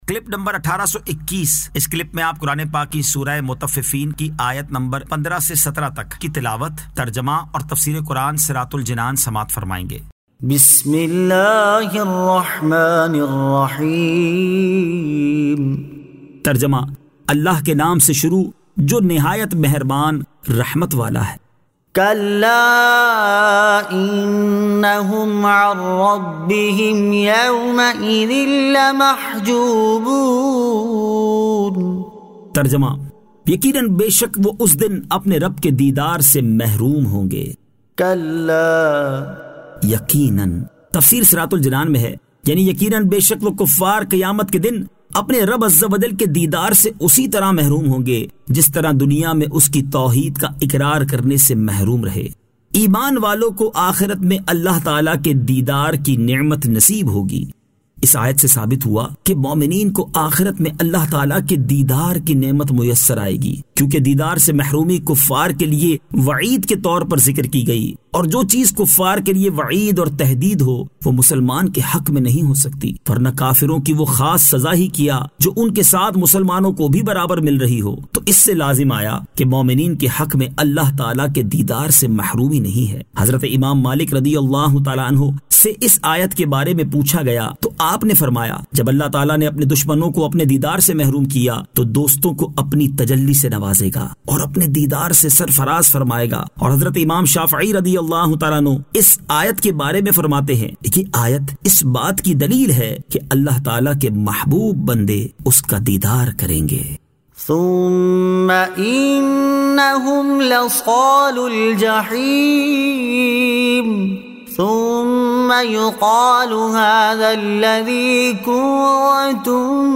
Surah Al-Mutaffifeen 15 To 17 Tilawat , Tarjama , Tafseer